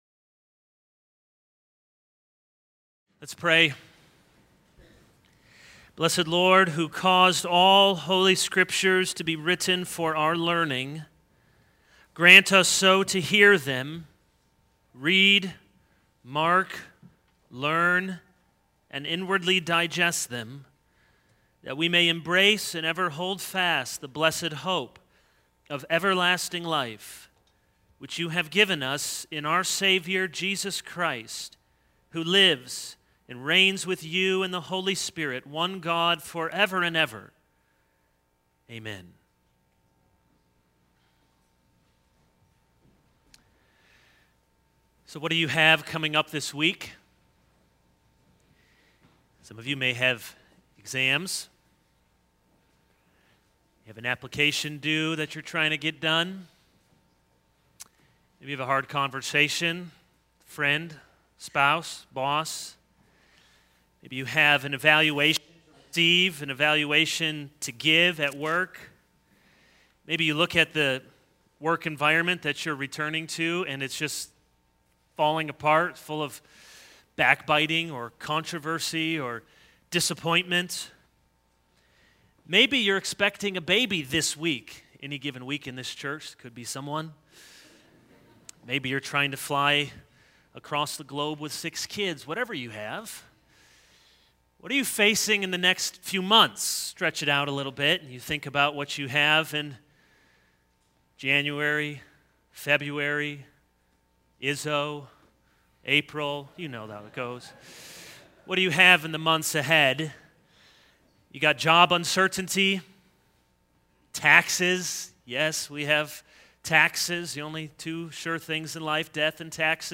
This is a sermon on Exodus 9:13-10:29.